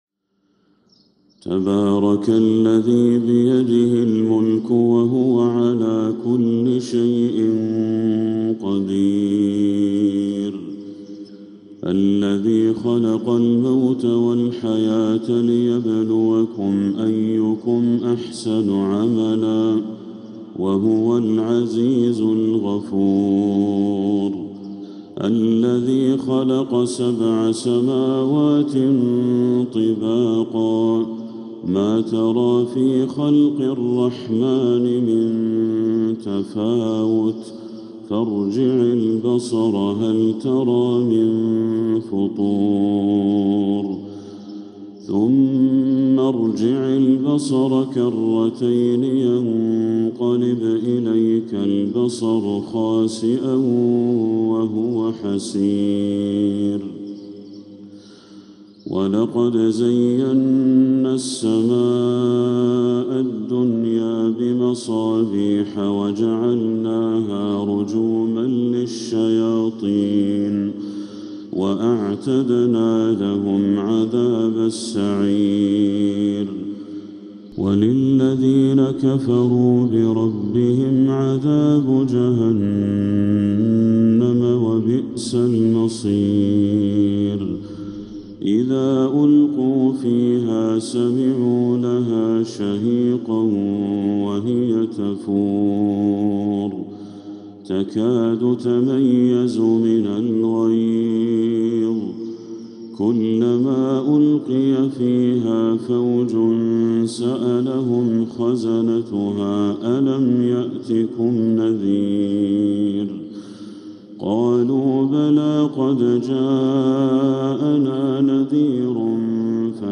جزء تبارك لفضيلة الشيخ بدر التركي من المسجد الحرام > السور المكتملة للشيخ بدر التركي من الحرم المكي 🕋 > السور المكتملة 🕋 > المزيد - تلاوات الحرمين